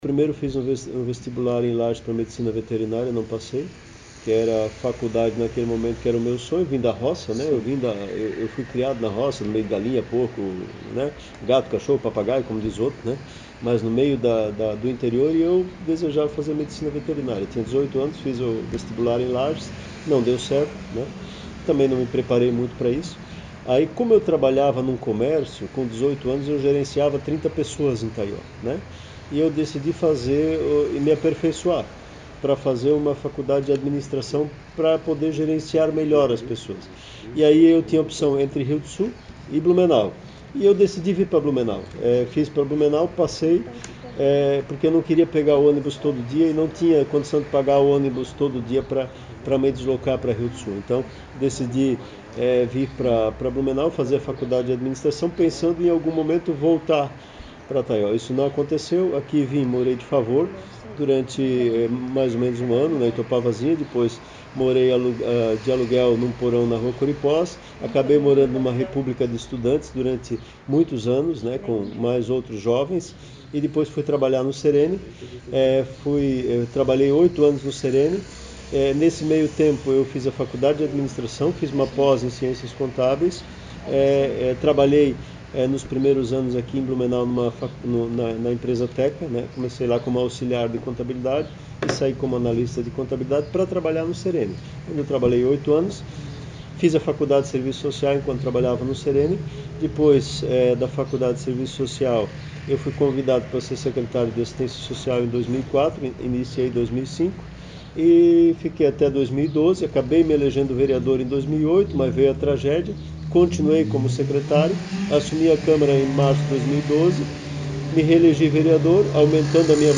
E foi ele mesmo quem contou essa trajetória.
SONORA-PREFEITO.mp3